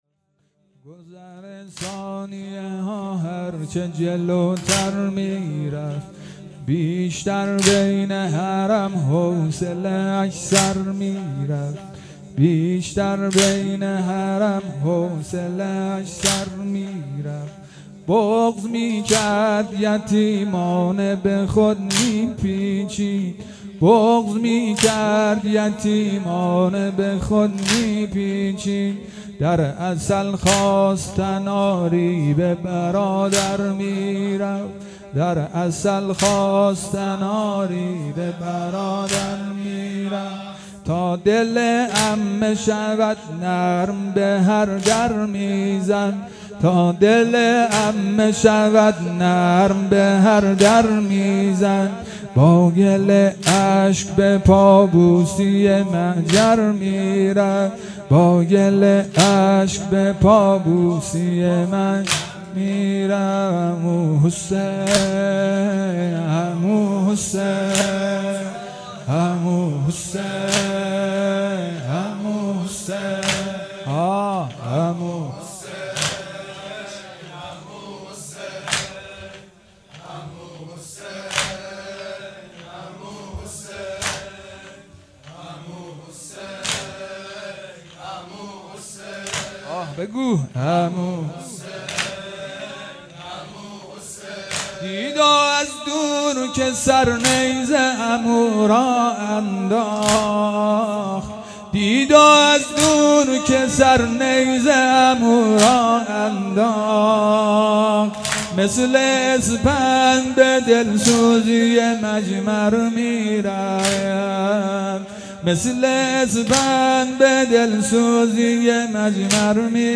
شب پنجم محرم الحرام 1441